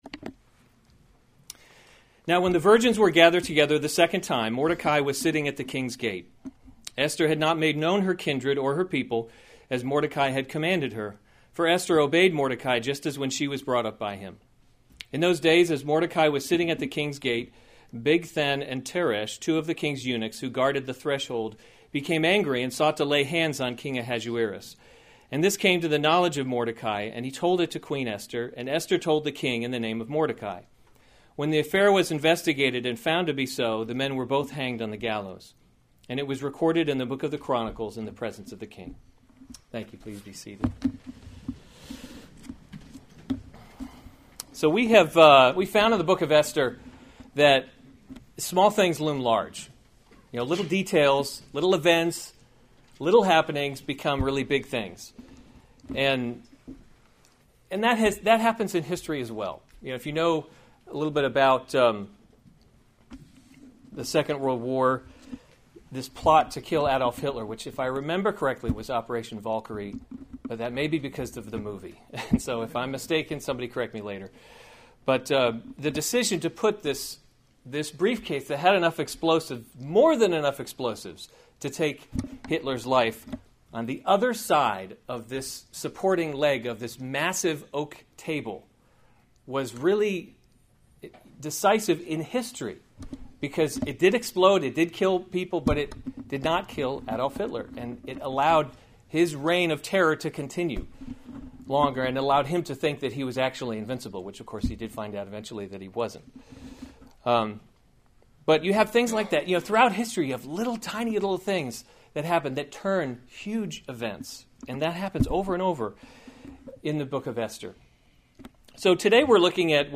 October 8, 2016 Esther: God’s Invisible Hand series Weekly Sunday Service Save/Download this sermon Esther 2:19-23 Other sermons from Esther Mordecai Discovers a Plot 19 Now when the virgins were gathered […]